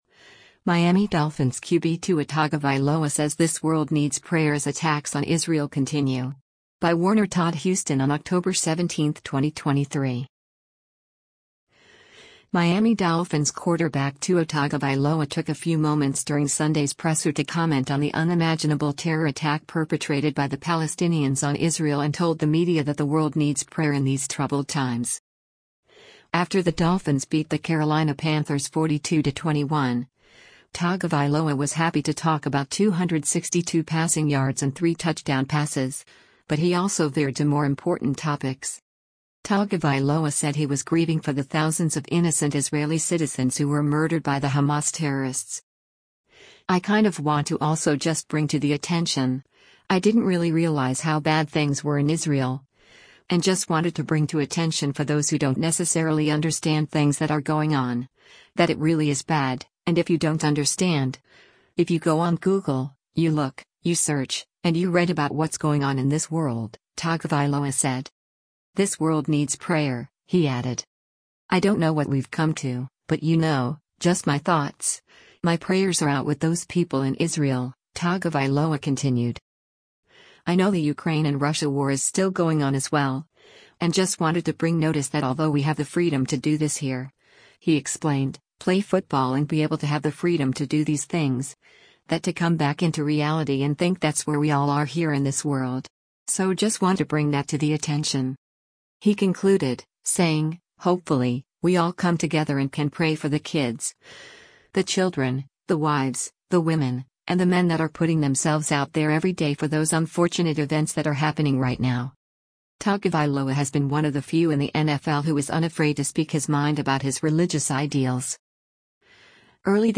Miami Dolphins quarterback Tua Tagovailoa took a few moments during Sunday’s presser to comment on the unimaginable terror attack perpetrated by the Palestinians on Israel and told the media that the world “needs prayer” in these troubled times.